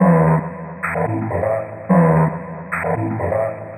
• chopped vocals ping pong house delayed - Em - 127.wav
chopped_vocals_ping_pong_house_delayed_-_Em_-_127_om4.wav